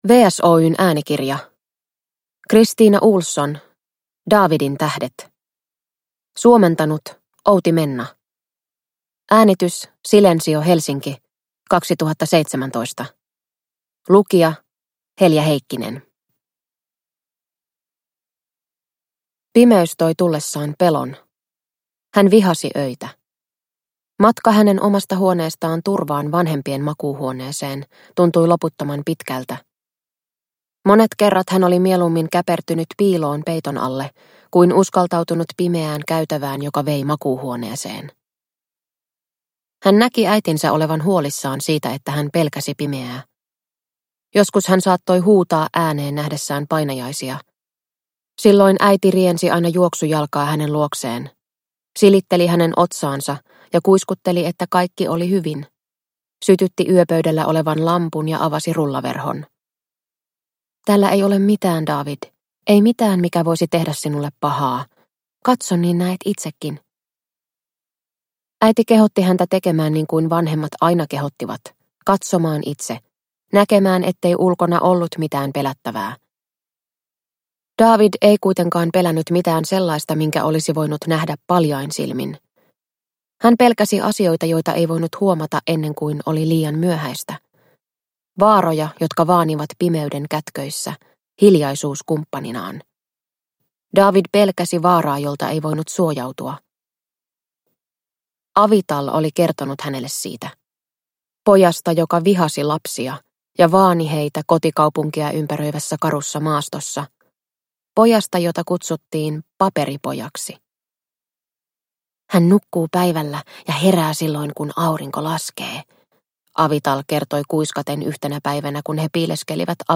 Daavidintähdet – Ljudbok